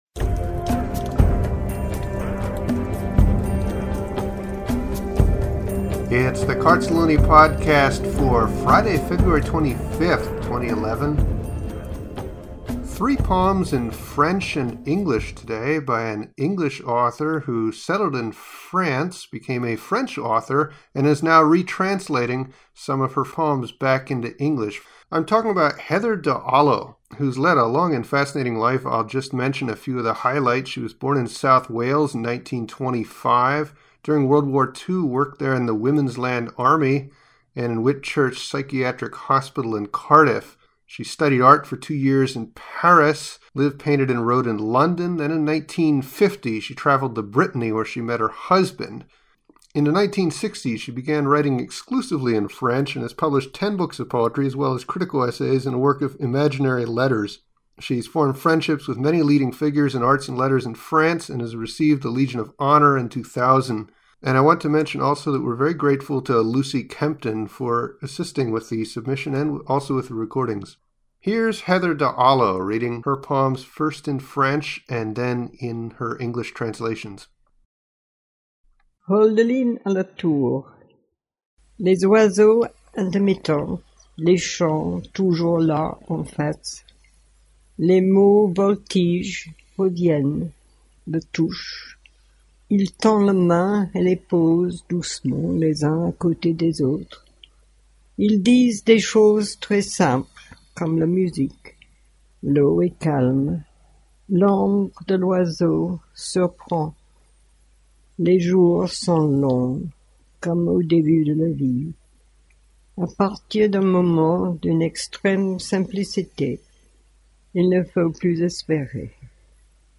Especially loved listening to her read.